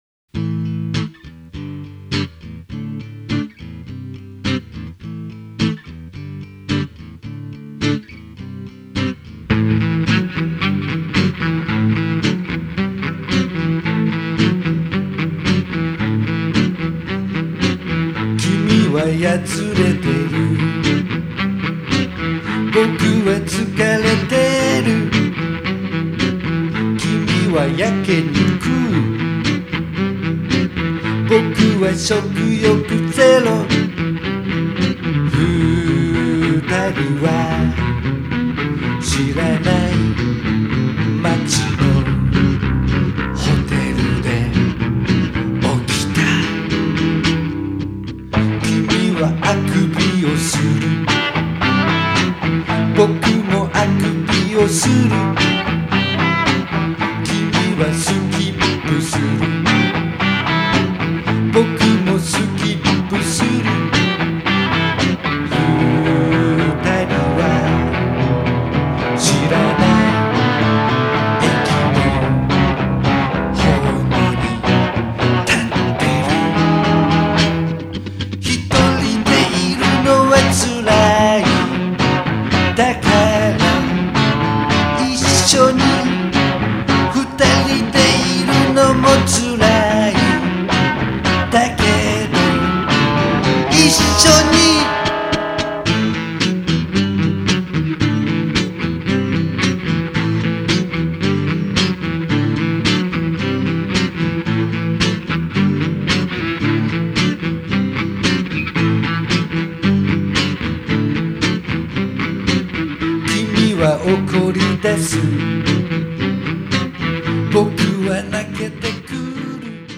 エレキ・チェロの導入。